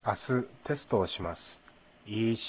下関弁辞典
発音